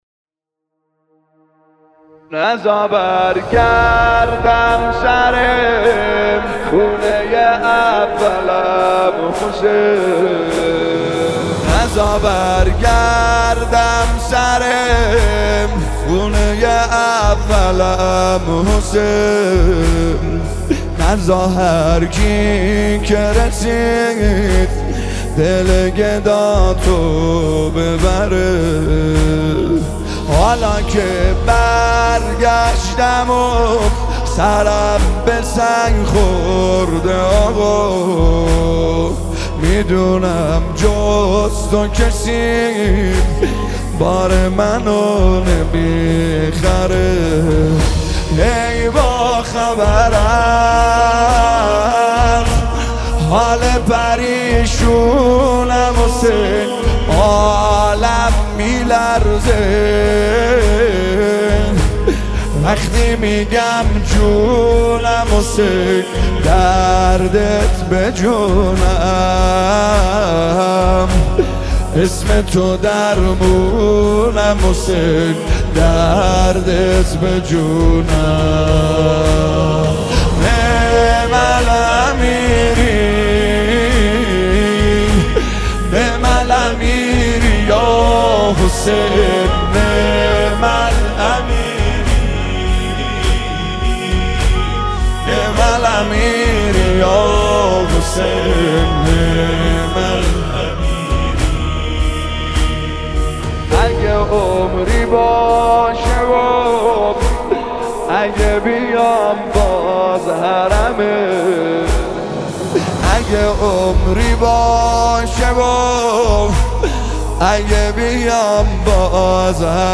نماهنگ مذهبی